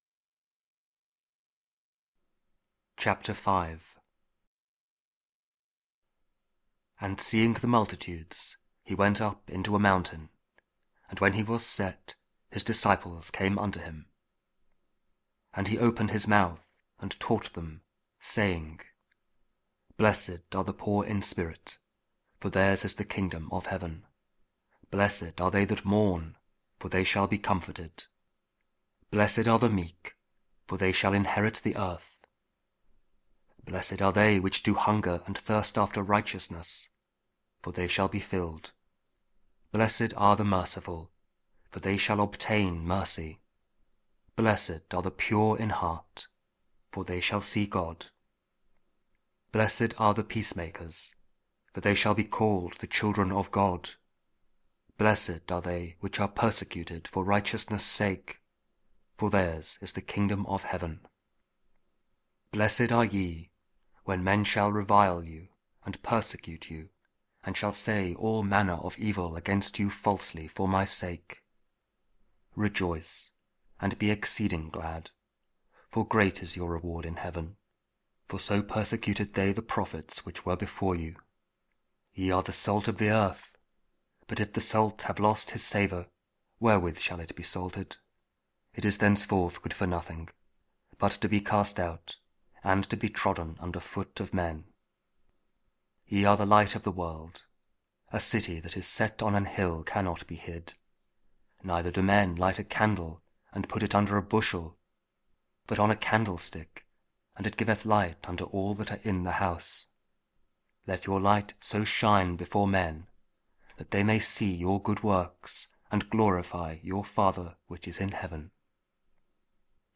Matthew 5: 1-12 – Week 10 Ordinary Time, Monday (King James Audio Bible KJV, Spoken Word)